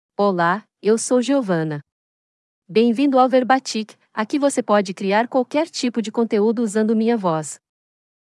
GiovannaFemale Portuguese AI voice
Giovanna is a female AI voice for Portuguese (Brazil).
Voice sample
Female
Giovanna delivers clear pronunciation with authentic Brazil Portuguese intonation, making your content sound professionally produced.